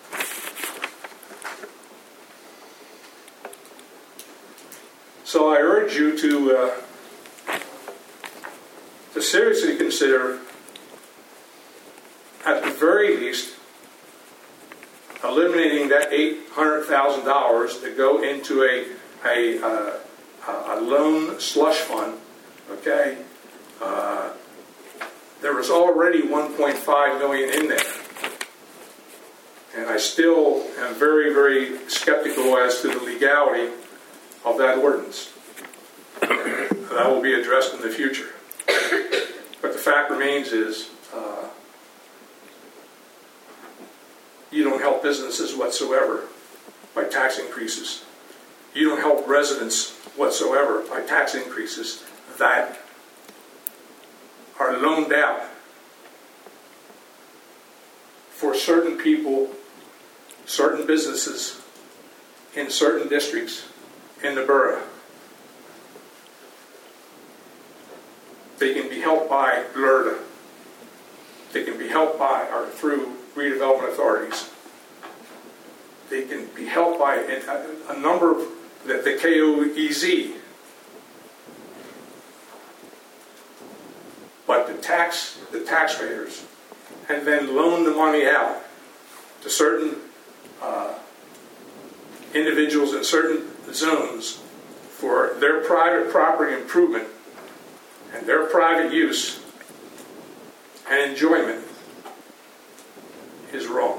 During the meeting, several citizens spoke about issues on the preliminary agenda.